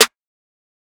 MZ Snare [Metro Accent Lo].wav